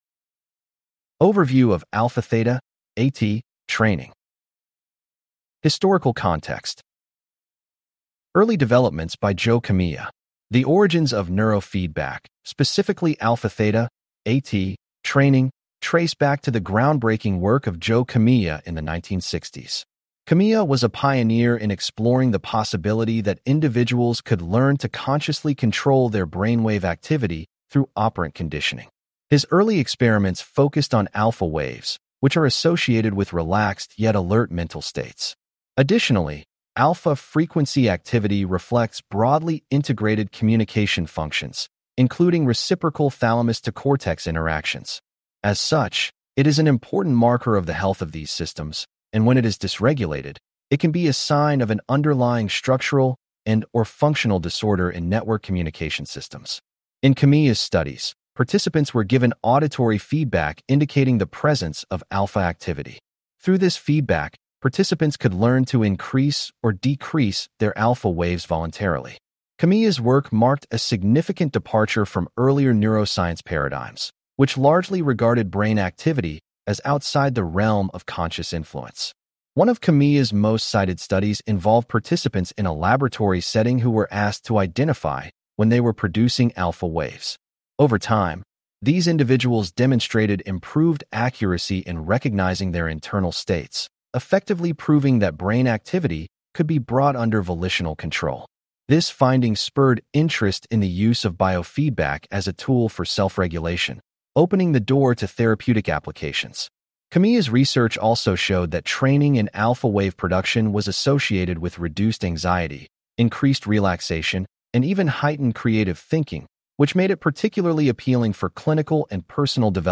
This unit covers Underlying Theory, Cross-Frequency Synchronization, Local Field Potential, Global and Local Synchronization, History and Development, Benefits of A-T Training, Comparison of Interventions, A-T Training Protocols, Expected Results, Applications, Cautions, Self-Medication, Substance/Behavioral Use Disorders, and Final Thoughts. Please click on the podcast icon below to hear a full-length lecture.